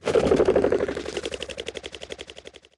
На этой странице собраны разнообразные звуки фазанов – от характерного квохтания до резких тревожных криков.
Звук трясущего крылья фазана